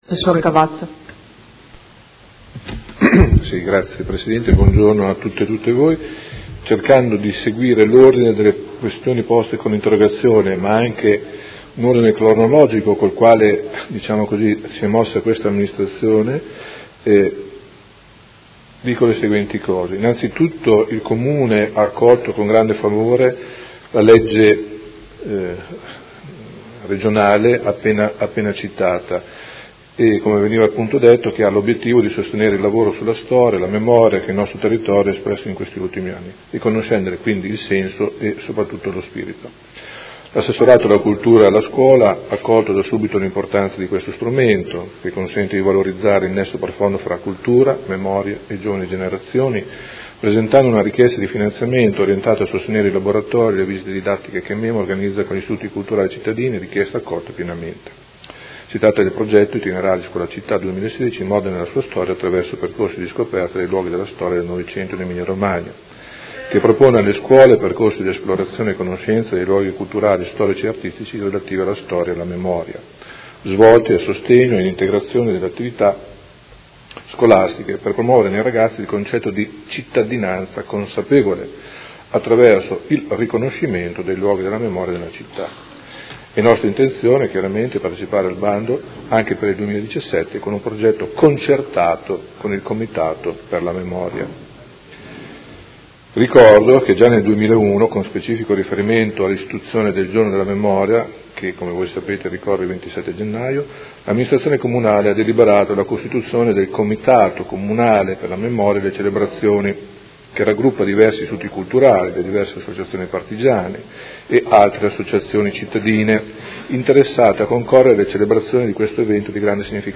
Seduta del 16/02/2017. Risponde a interrogazione delle Consigliere Di Padova, Liotti e Pacchioni (P.D.) avente per oggetto: Legge Regionale sulla Memoria del 900